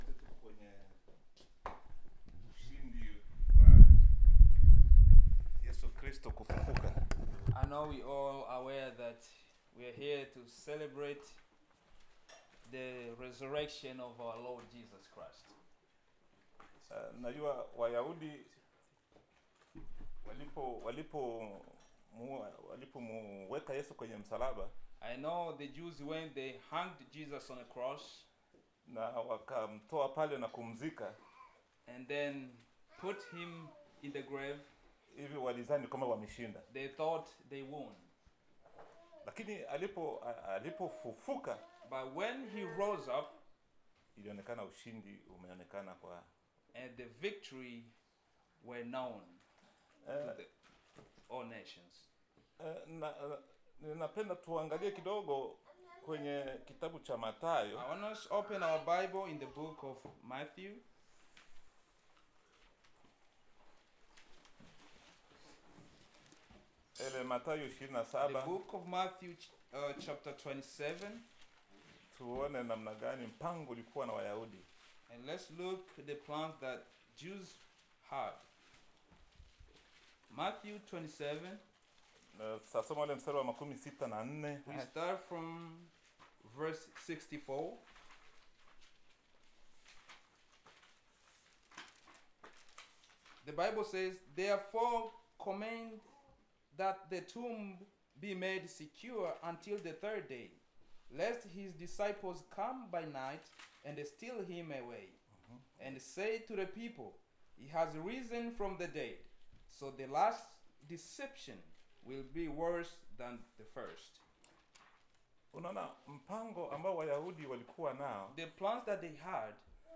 Topical Sermon Collection